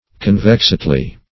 \Con*vex"ed*ly\